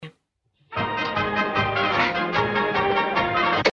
Прошу подскажите название мелодии 30 - 40-х. Есть только маленький фрагмент.
Мне кажется, что это отрывок из мюзикла или оперетты.
retro.mp3